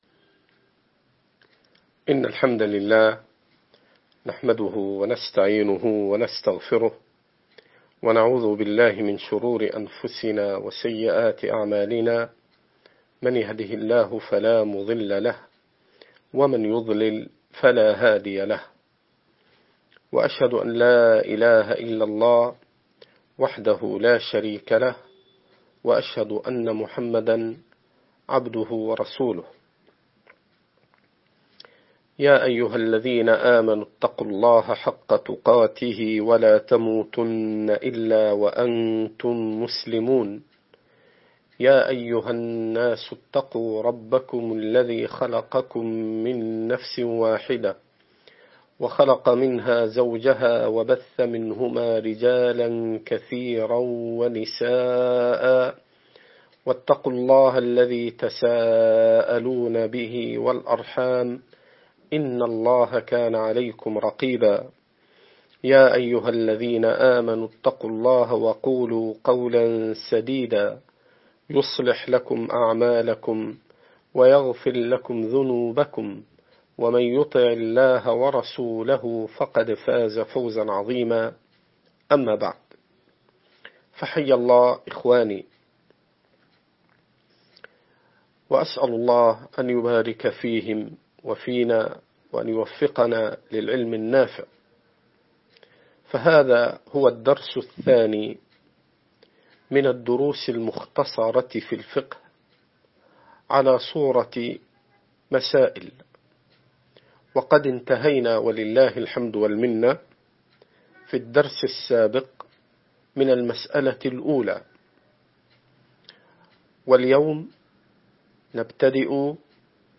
مسائل في الفقه - الدرس الثاني